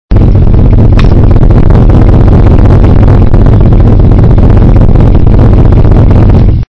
TRUCK3.mp3